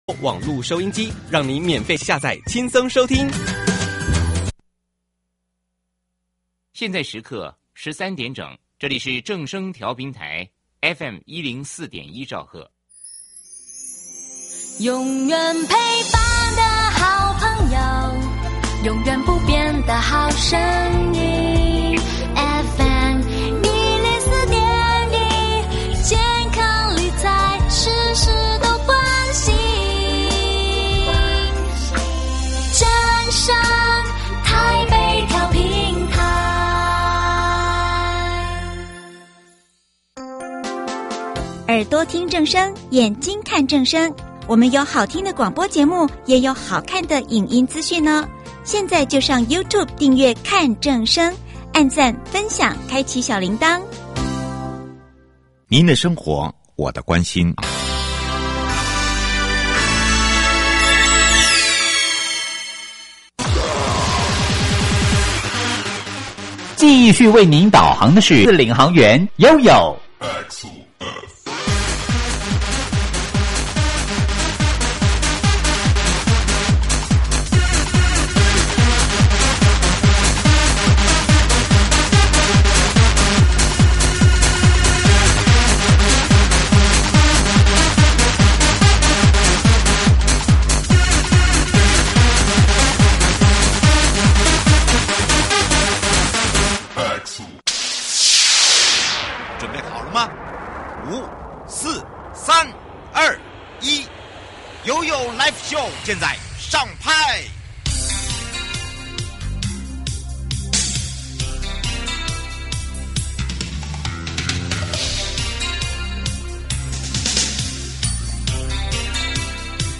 受訪者： 國土署都市基礎工程組 新竹市政府交通處林俊源副處長(四)